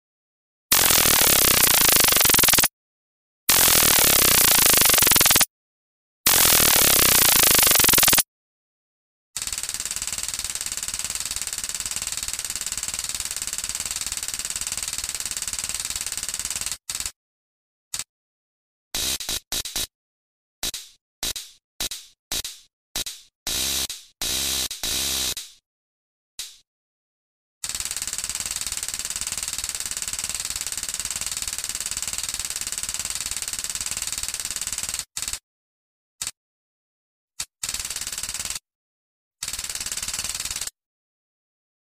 Âm thanh tiếng phóng điện Dùi cui điện
Thể loại: Đánh nhau, vũ khí
Description: Âm thanh tiếng phóng điện dùi cui, tiếng roi điện rẹt rẹt vang lên khi có dòng điện phát ra, tiếng động điện giật.
tieng-phong-dien-dui-cui-dien-www_tiengdong_com.mp3